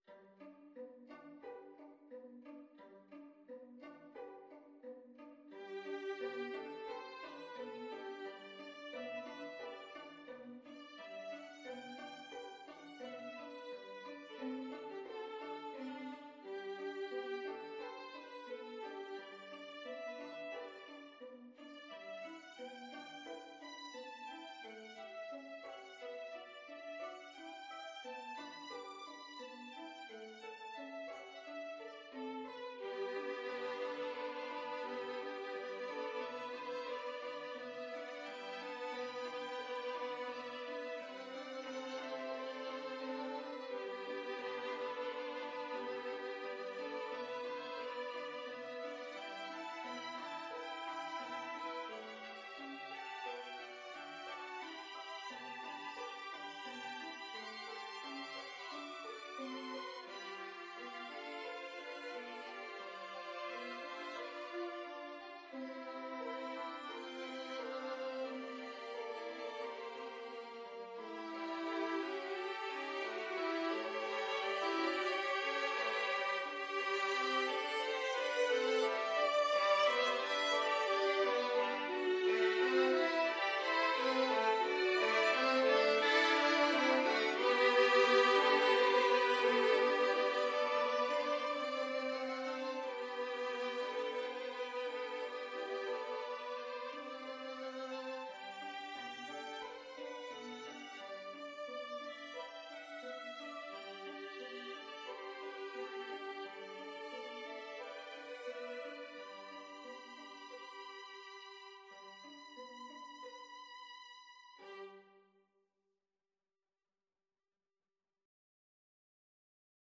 Violin 1Violin 2Violin 3Violin 4Violin 5
4/4 (View more 4/4 Music)
Classical (View more Classical Violin Ensemble Music)